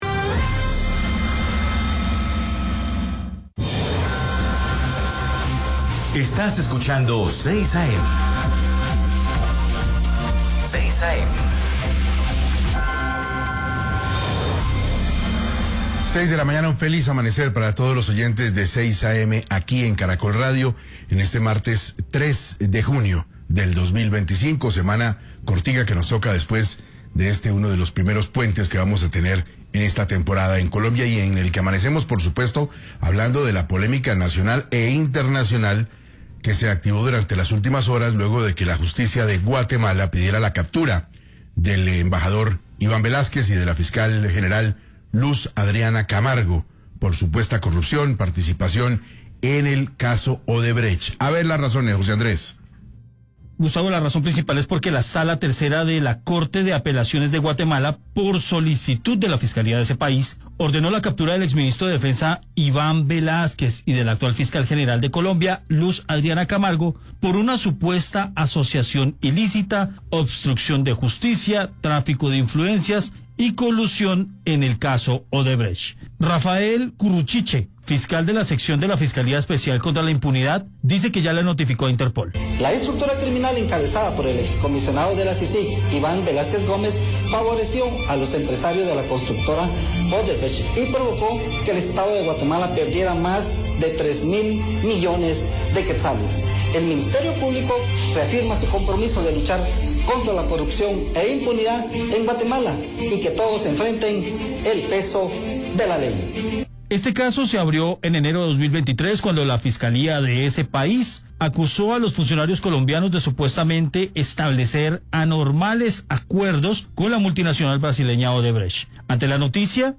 En 6AM de Caracol Radio se consultó con un periodista de la nación centroamericana, quien dio más detalles sobre el caso.